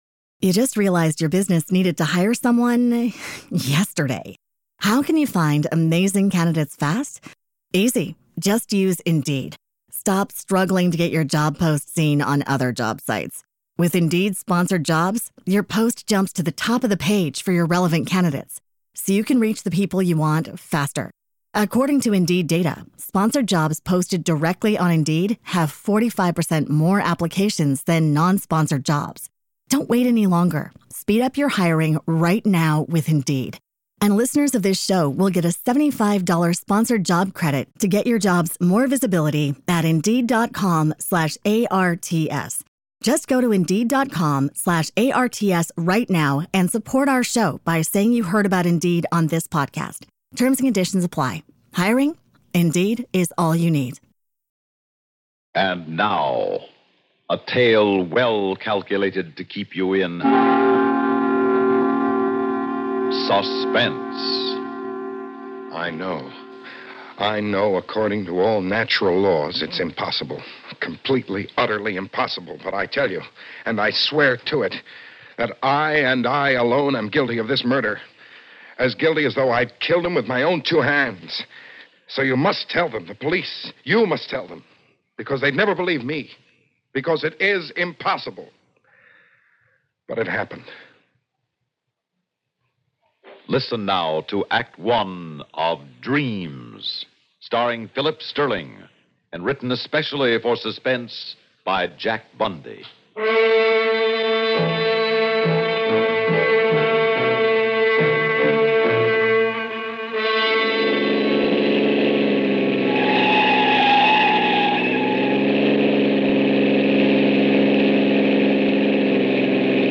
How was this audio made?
On this episode of the Old Time Radiocast we present you with two stories from the classic radio program Suspense!